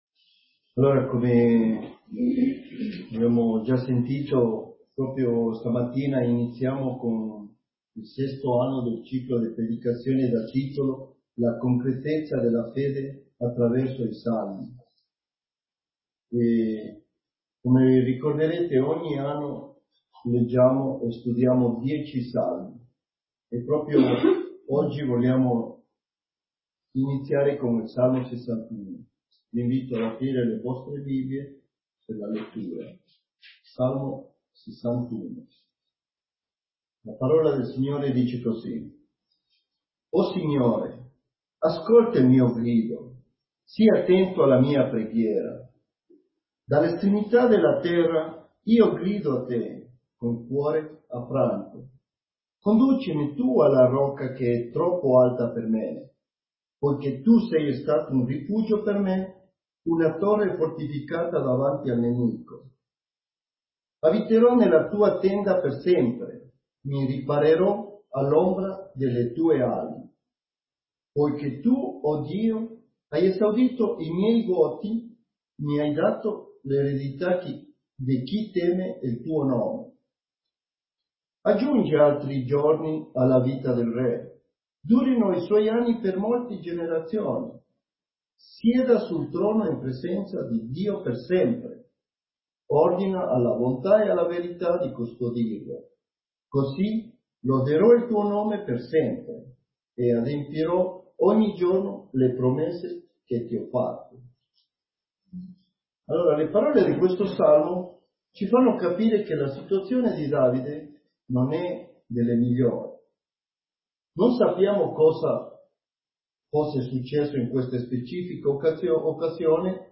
Predicatori